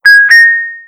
oscarpilot/selfdrive/frogpilot/assets/custom_themes/tesla_theme/sounds/engage.wav